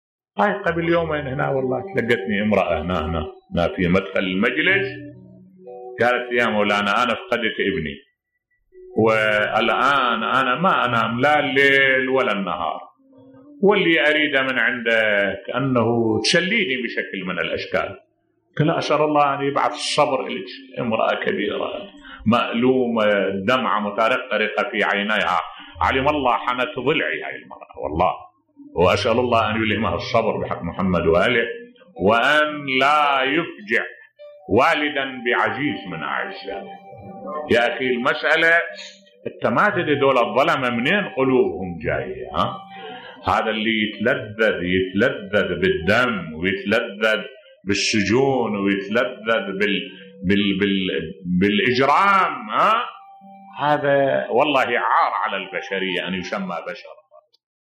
ملف صوتی المرأة المفجوعة بولدها التي حنت ضلع الشيخ الوائلي موقف مؤلم بصوت الشيخ الدكتور أحمد الوائلي